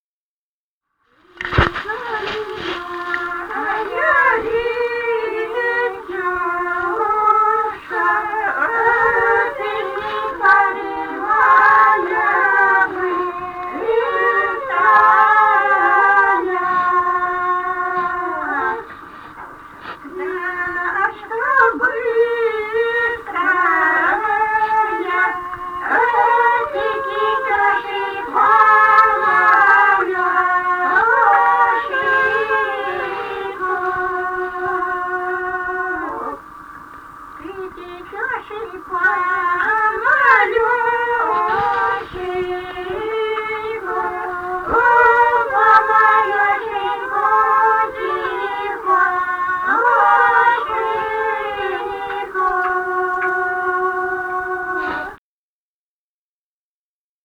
Музыкальный фольклор Климовского района 064. «Ты река ли, моя речушка» (свадебная).